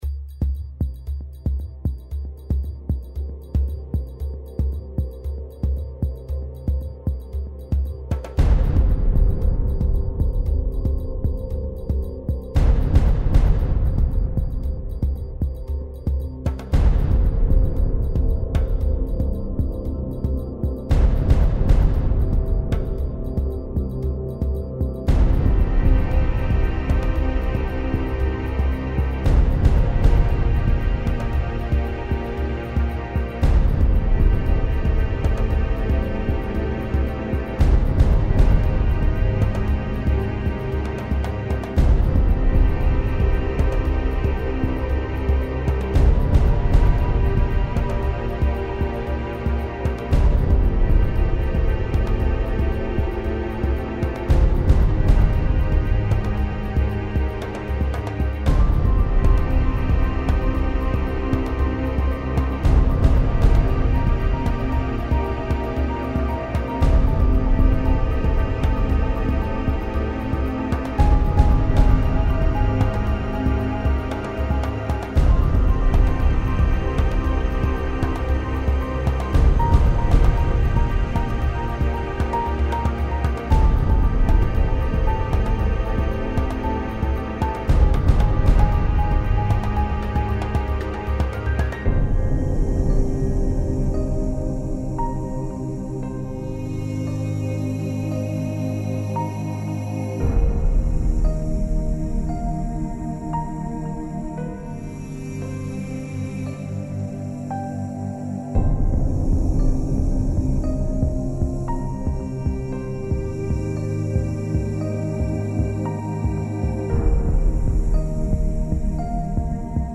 epique - percussions - profondeurs - aerien - ciel